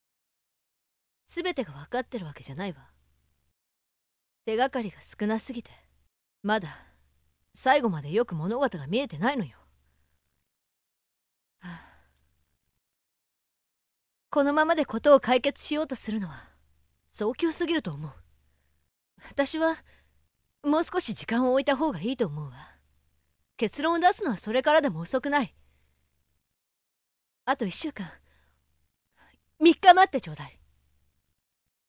● サンプルボイス ●
↓お試し同然の無理無理とか、音質も、ノイズ処理などが適当なのも混じってます。
女03【大人・中間】 【１】
【１】指示を出す人。